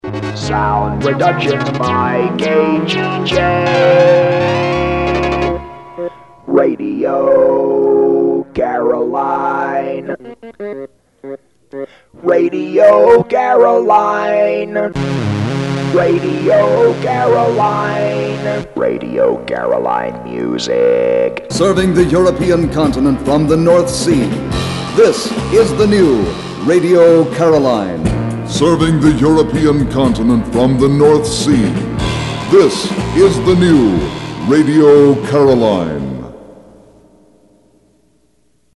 En sonovoxspecialist.